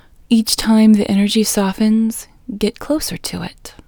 IN Technique First Way – Female English 15